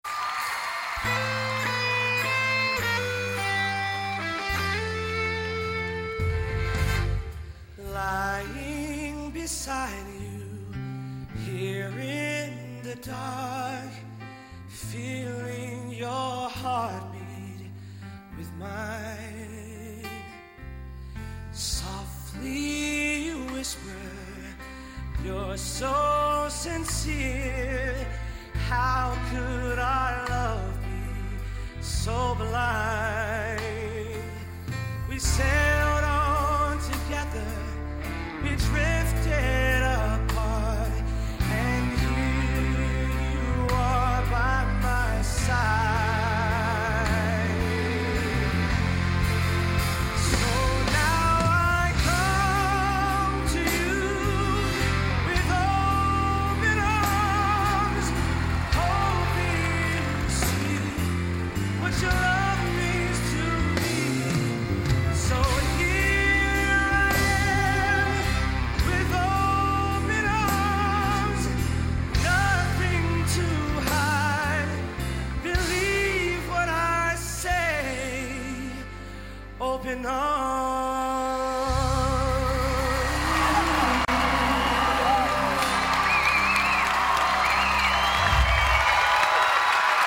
R & B Pop